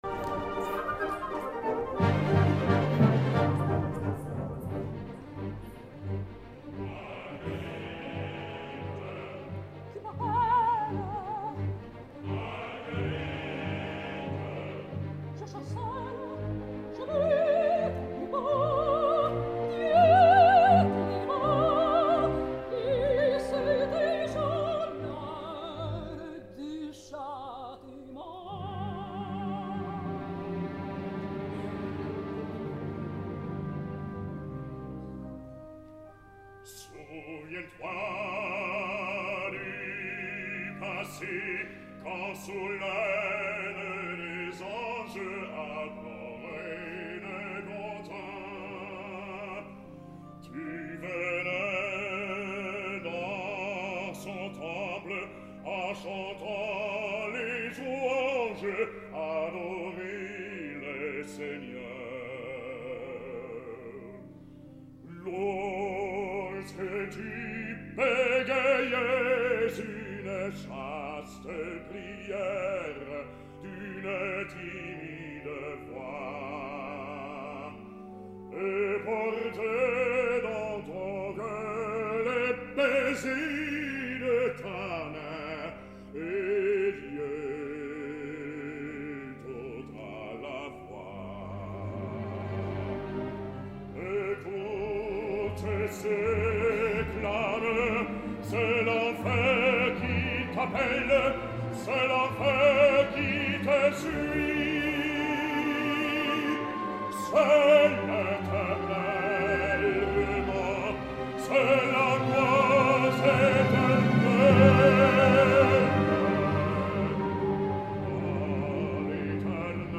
Marguerite: Angela Gheorghiu
Aguts magnífics i un centre preciós i vellutat, deixen massa sovint pas a uns canvis de veu mal resolts quan ha de fer front als greus i a unes preocupants afinacions aproximades a l’inici de la seva gran escena del tercer acte.
Escoltem-la en l’escena immediatament posterior, la de l’església, amb René Pape i el cor del Covent Garden: “Seigneur, daignez permettre”